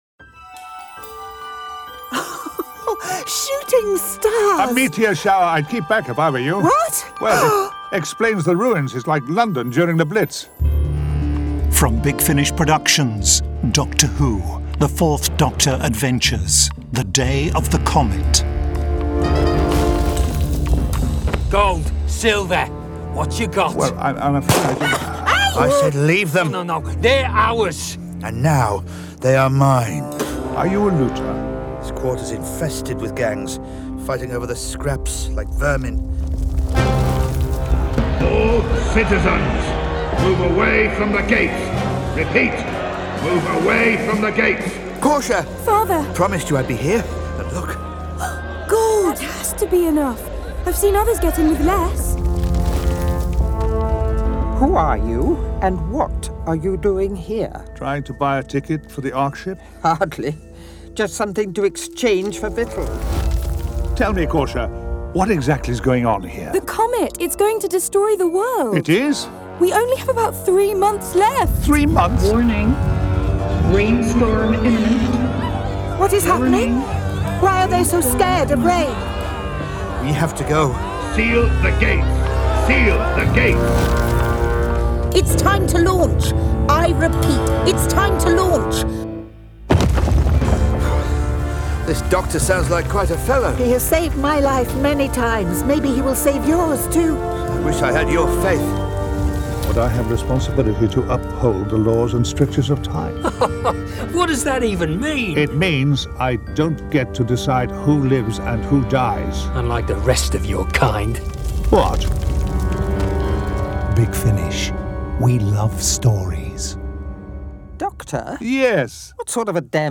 Starring Tom Baker Louise Jameson